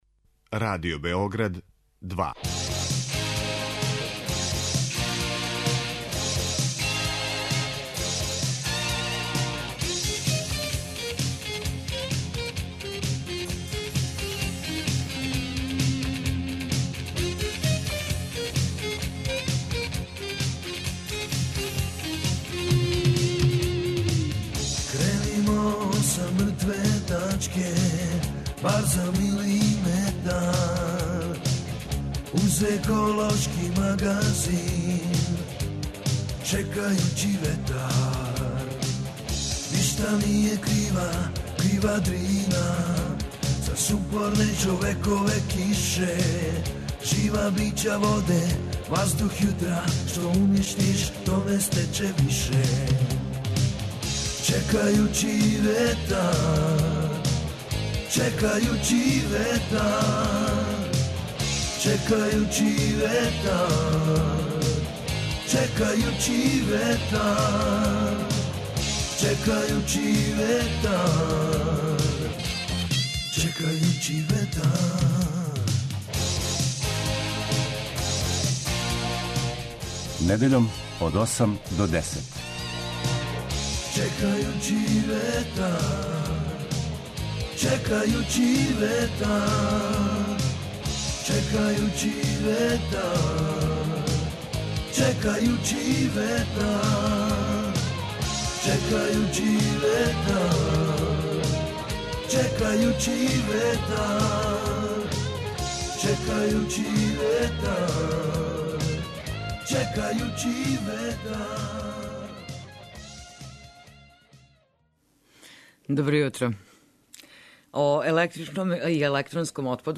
Еколошки магазин који се бави односом човека и животне средине, човека и природе.